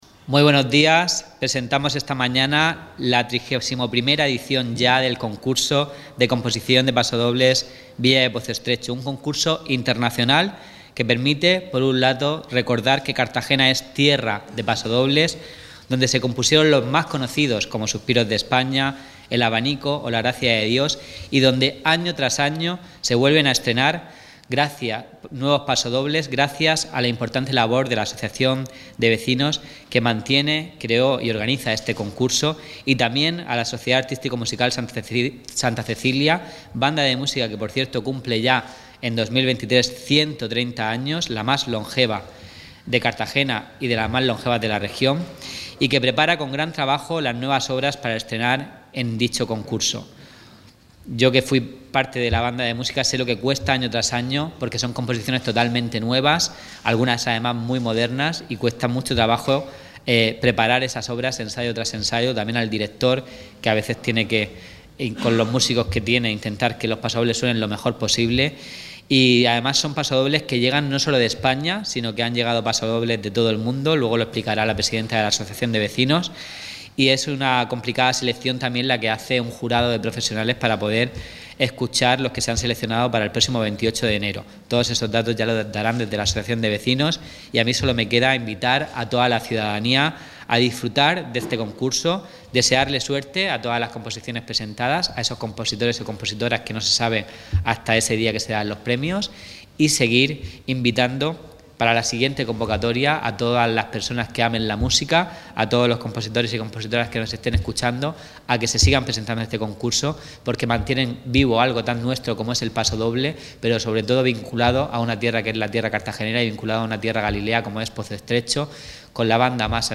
Presentación del Concurso Internacional de Pasodobles Villa de Pozo Estrecho
Así lo han presentado el concejal de Cultura, David Noguera Martínez